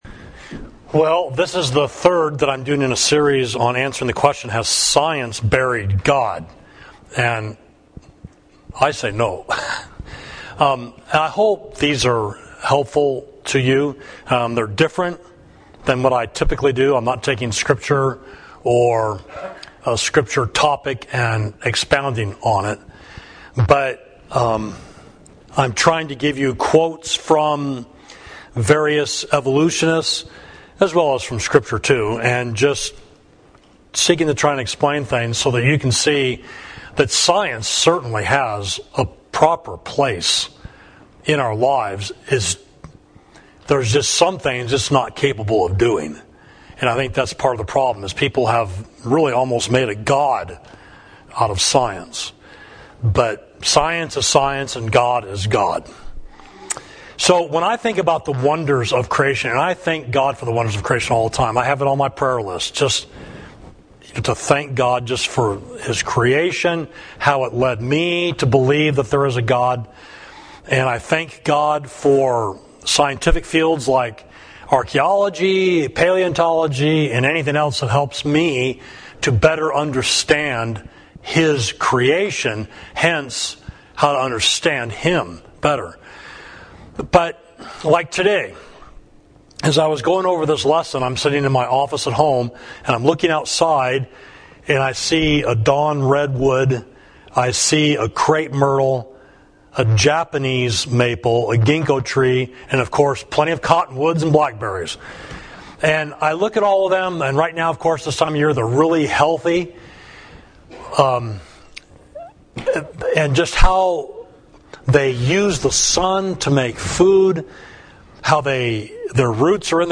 Sermon: Has Science Buried God?